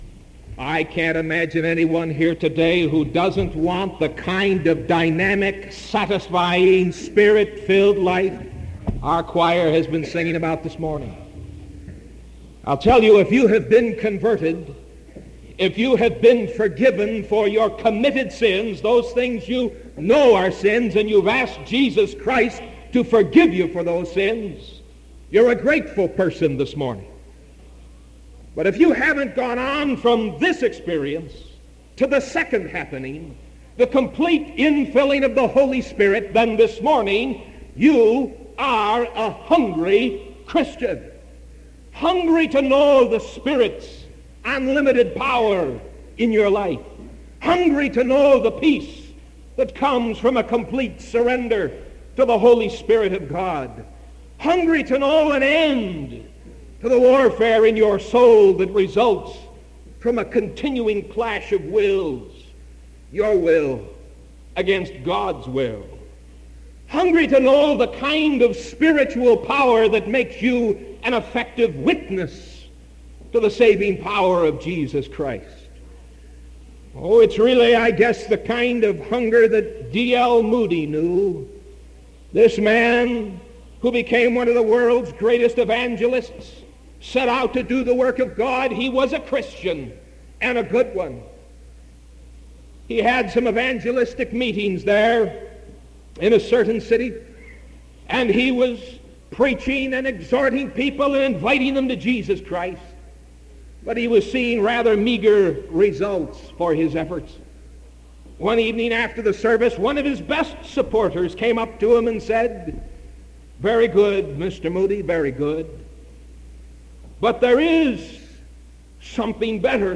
Sermon September 1st 1974 AM